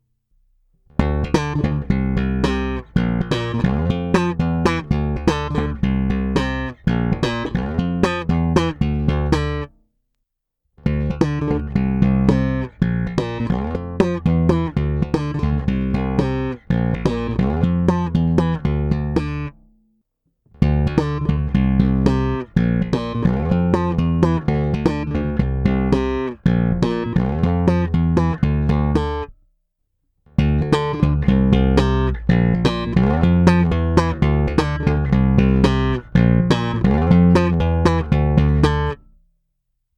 Baskytara je Fender American Professional II Precision Bass V s roundwound strunami Sadowsky Blue Label 40-125 ve výborném stavu. Postupně je v ukázkách čistý zvuk baskytary bez kompresoru, pak kompresor s režimy v pořadí NORMAL, MB a nakonec TUBESIM.
Ukázka slapu